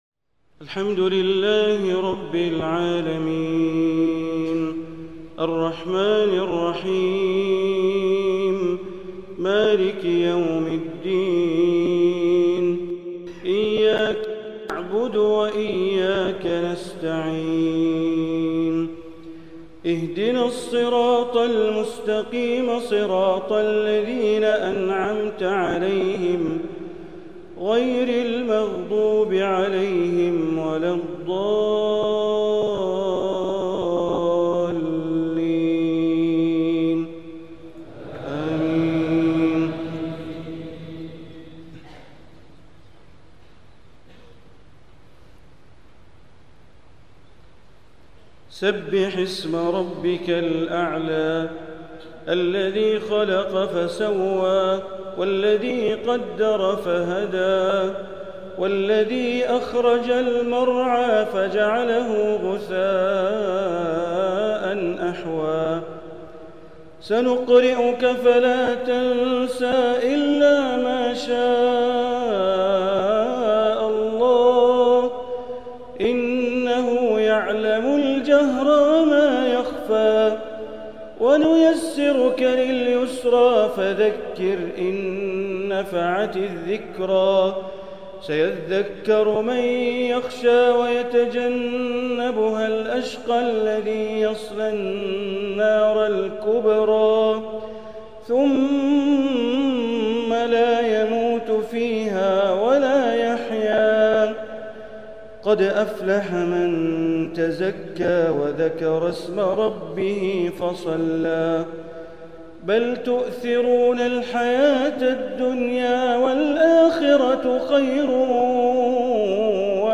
صلاة الجمعة للشيخ د. بندر بليلة 12 صفر 1446هـ من مملكة كمبوديا | سورتي الأعلى و الغاشية > زيارة الشيخ بندر بليلة الى دولة كمبوديا > المزيد - تلاوات بندر بليلة